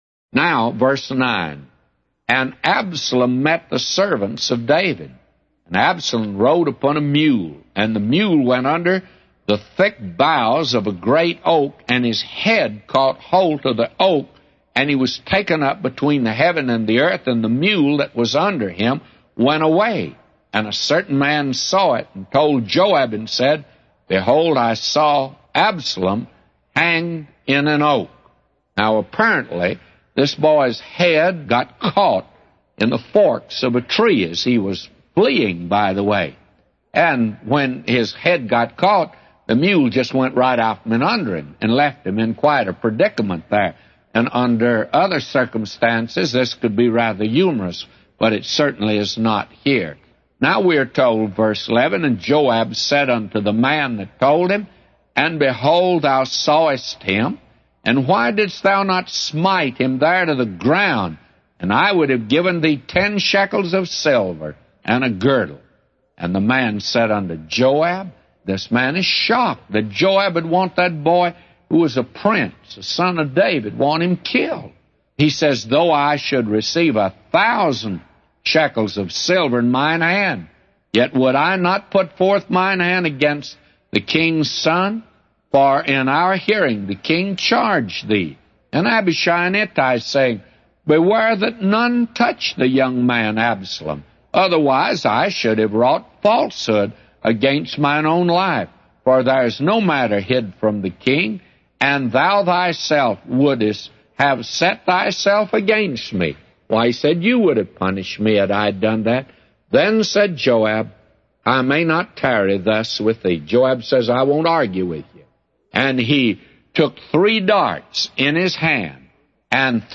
A Commentary By J Vernon MCgee For 2 Samuel 18:9-999